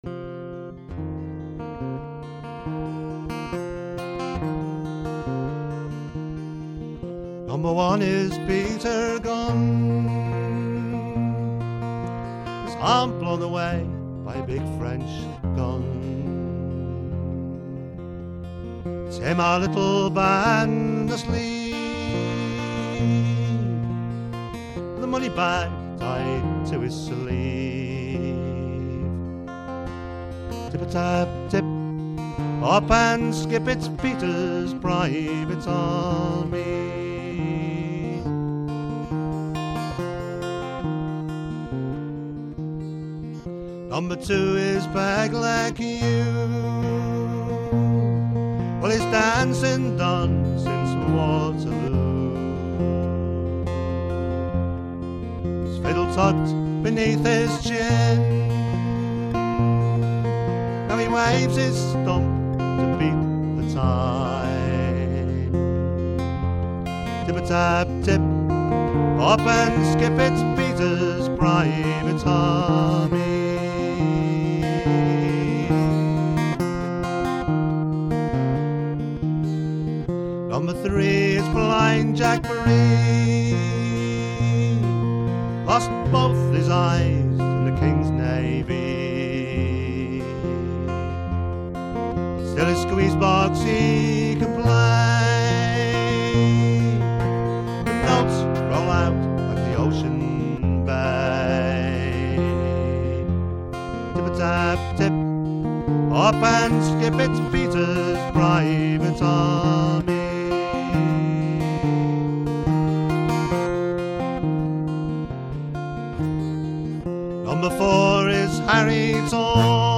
He has an excellent voice, claiming that he is not a guitarist and the guitar is merely there as background support. His intricate playing, in a variety of styles, suggests otherwise.